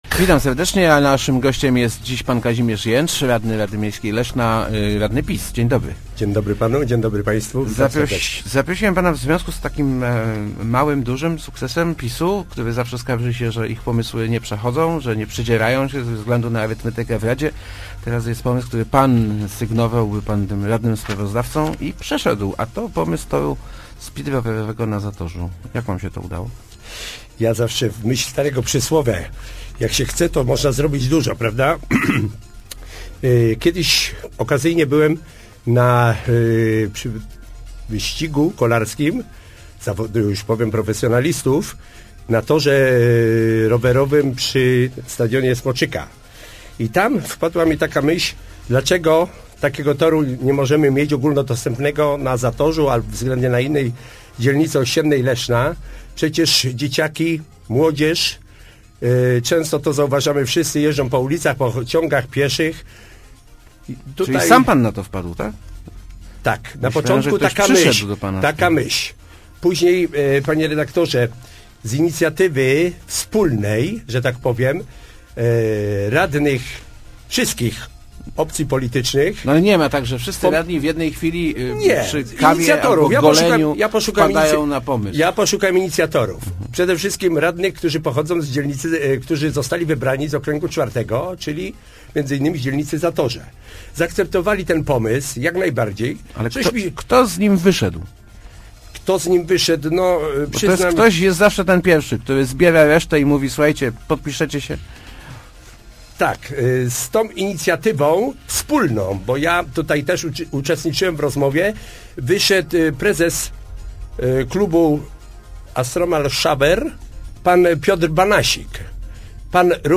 W wa�nych dla mieszka�ców sprawach mo�na si� porozumie� – zapewnia� w Rozmowach Elki Kazimierz J�cz, leszczy�ski radny PiS. Przyk�adem takiego ponadpartyjnego porozumienia by�a sprawa toru speedrowerowego na Zatorzu, gdzie inicjatyw� J�cza wsparli radni SLD oraz niezale�ni.